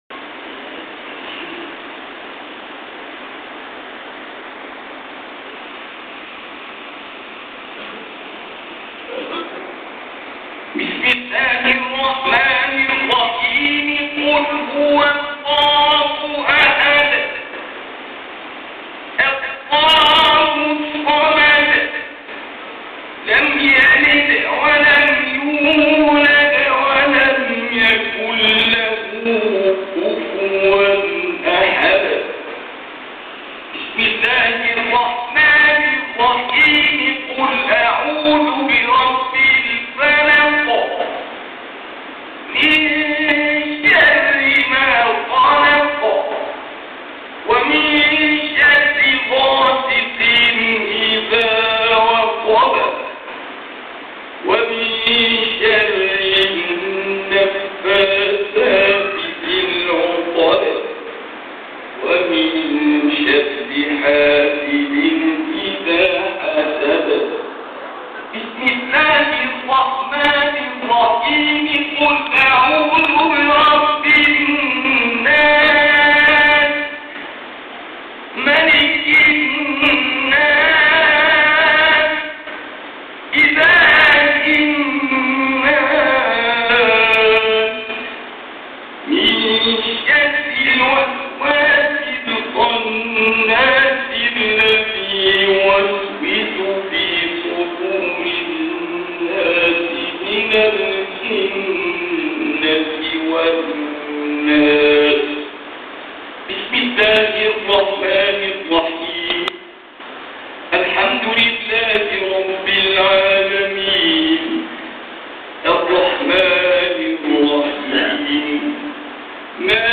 ختام القرآن في عام 1431 بمسجد مساكن القللي